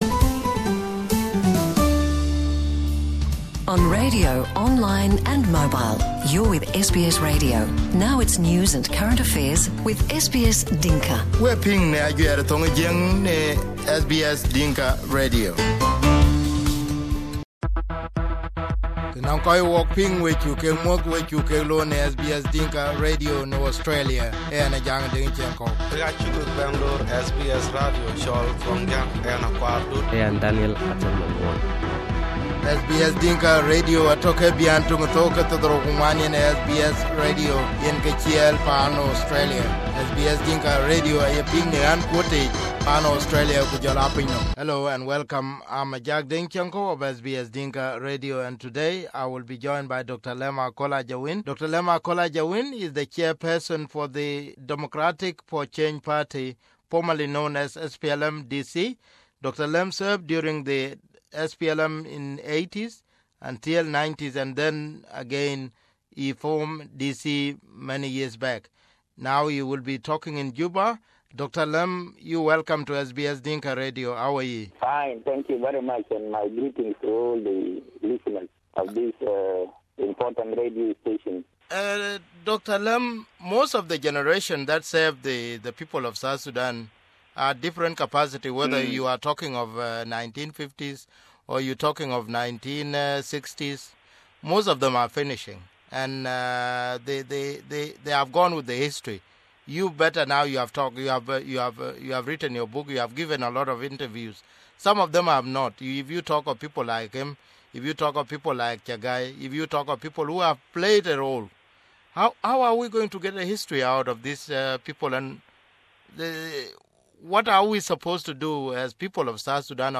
In 2016 when South Sudanese President Salva Kiir and Dr Riek Machar came up with the proposal to divided South Sudan into more than ten states, Dr Lam Akol Ajawin rejected the idea. As the chairman for the Democratic Change Party, Dr Lam Akol was asked in this exclusive interview, if the proposal made by the Government and the opposition leader were good for South Sudan.